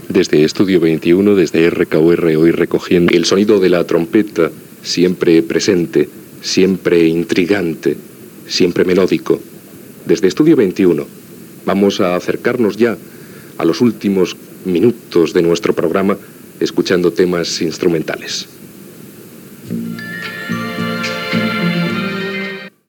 Identificació de la ràdio i del programa, presentació musical.